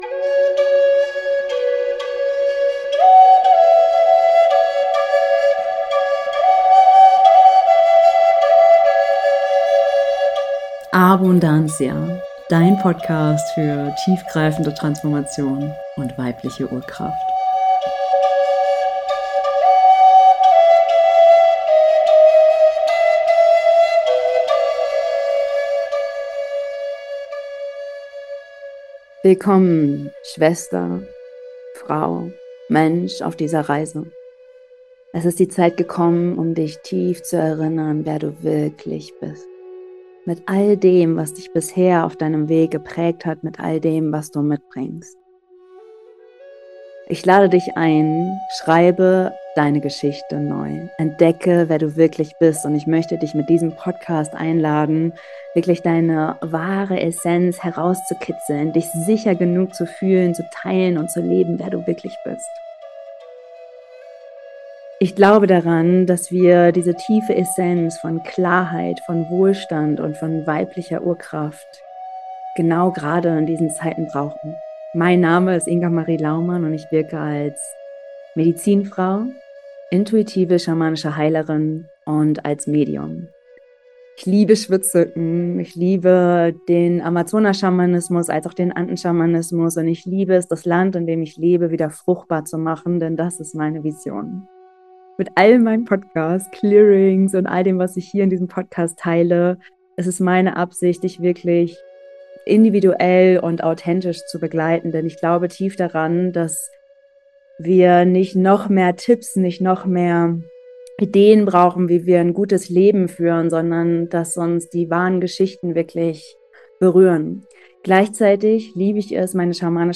6# Gesunder Schlaf und heilende Träume - Clearing und Einschlafmeditation ~ ABUNDANCIA - Dein Podcast für weibliche Urkraft & tiefgreifende Transformation!